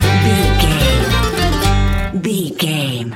Ionian/Major
banjo
violin
double bass
acoustic guitar